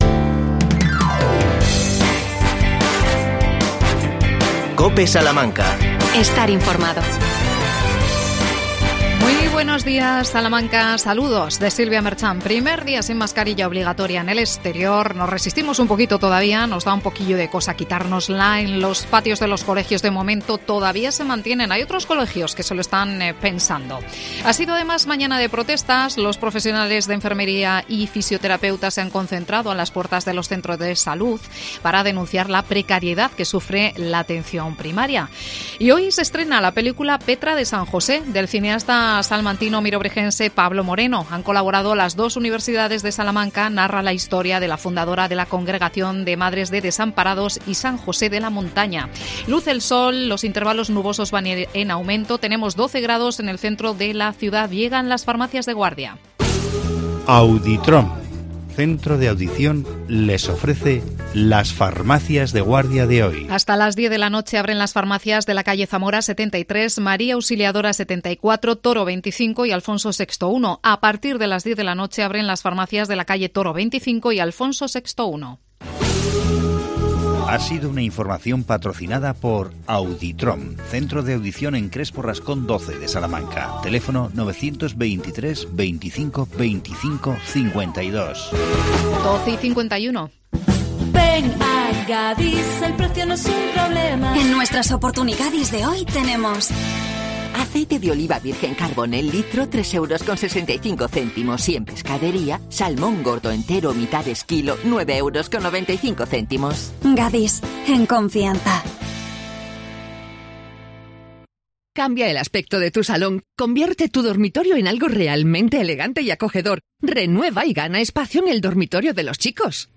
AUDIO: Día Mundial de la Mujer y la Niña en la Ciencia. Entrevistamos